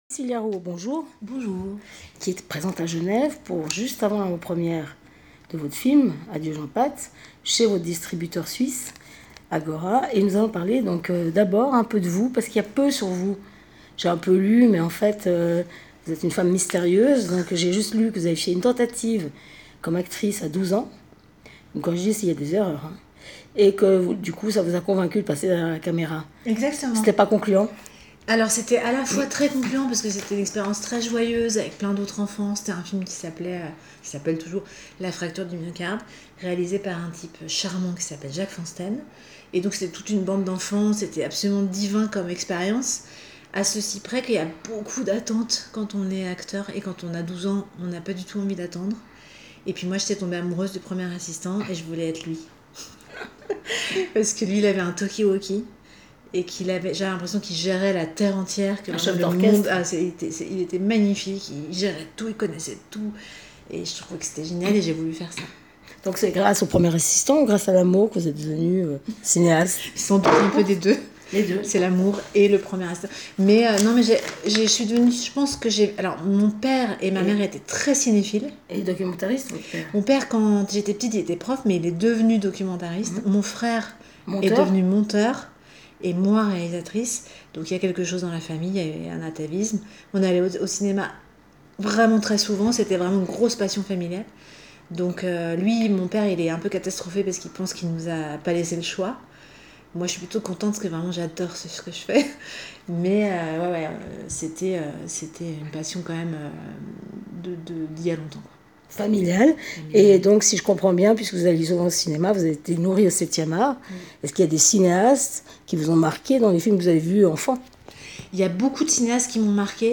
Rencontre - j:mag